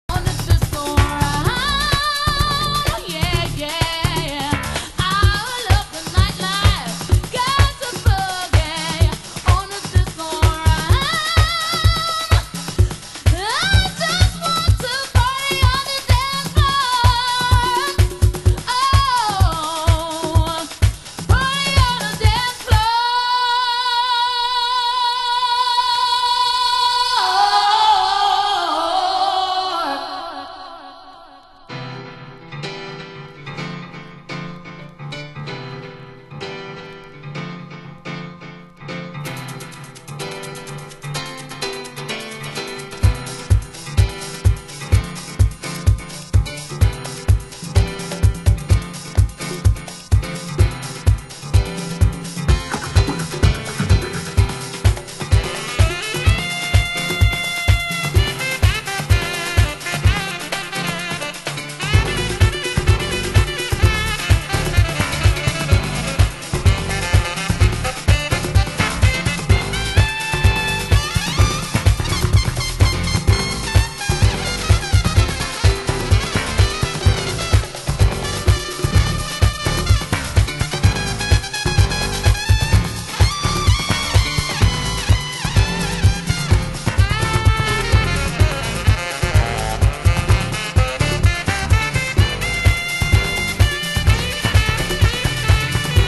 Bonus Beats
盤質：概ね良好、少しチリパチノイズ有　　ジャケ：概ね良好/インナースリーブに一部破れ